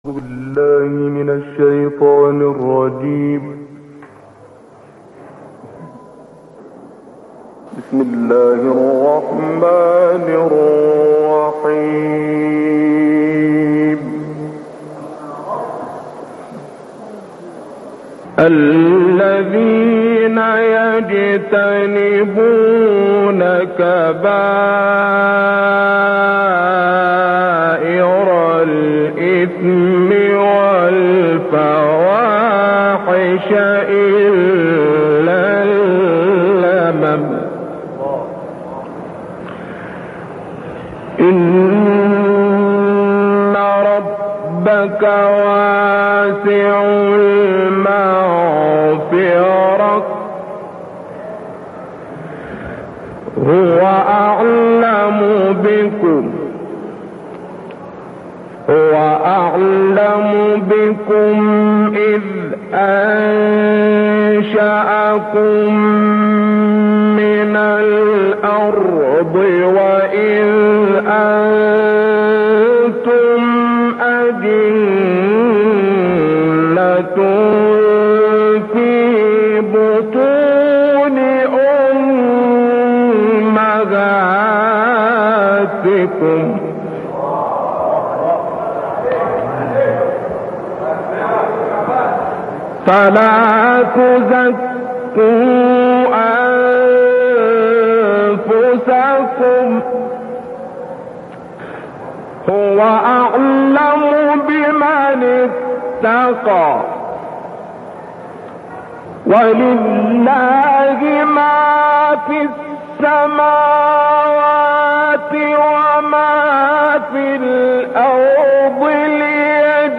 Səmavi səsli qarini xatırlayaq
Bu misirli qari tilavətdə bacarıqlı və xüsusi qiraət üsuluna malik idi.